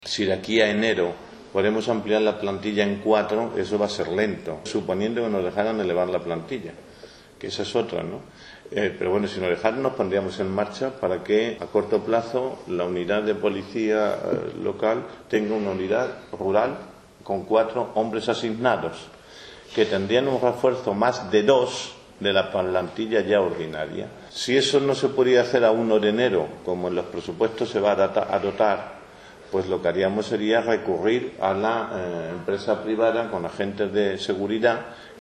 El regidor municipal informaba de estas medidas en rueda de prensa tras la reunión que mantuvo ayer con los agricultores que les trasladaban su preocupación por el aumento del número de robos de motores en la época estival, por lo que han decidido crear patrullas nocturnas de civiles que estarán en contacto directo con la Policía Local a través de Whatsapp para identificar las matrículas de los vehículos sospechosos, que se sumará a la actual patrulla rural con la que cuenta un policía local y un guarda rural.